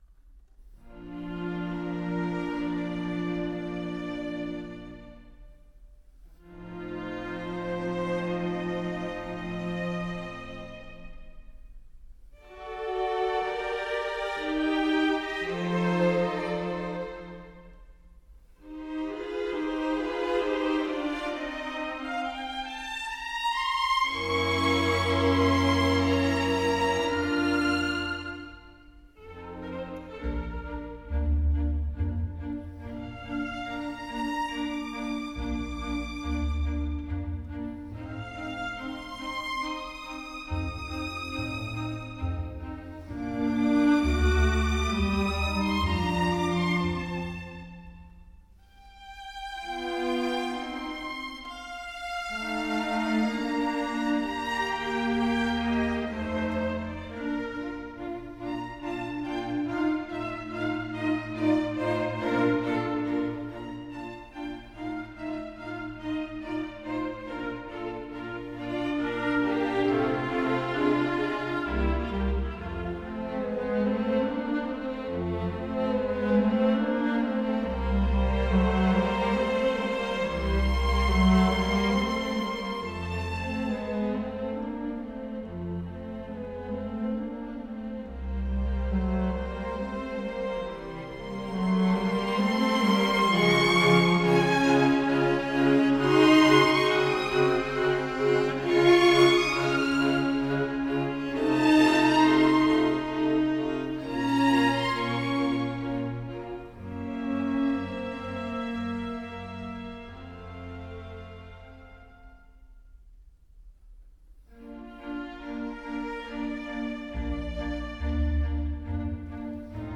II. Andante.